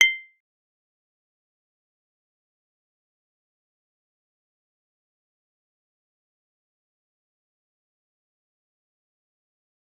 G_Kalimba-C7-f.wav